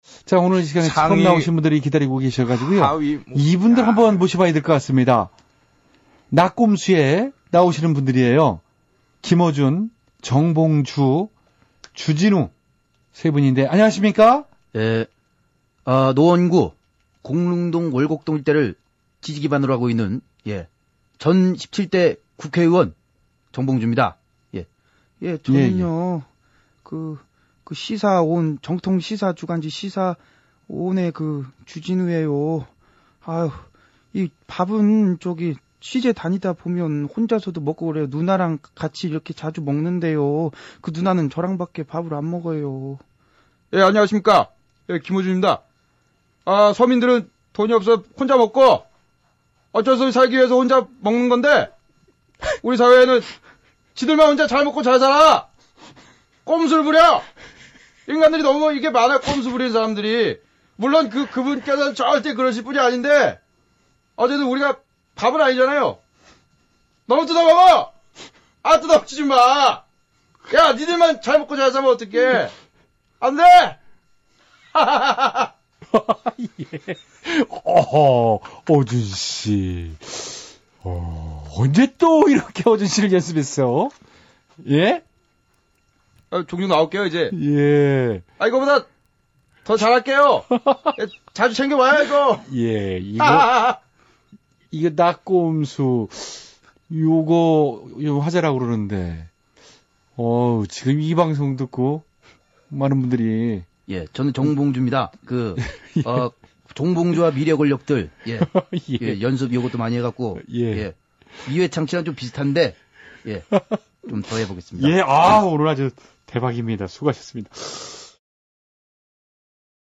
나꼼수 3인방 성대모사 들어보세요.ㅋㅋ
개그맨 안윤상이 성대모사한 거래요.
근데 정봉주 의원 성대 모사는 더 얌전한 것 같은데요.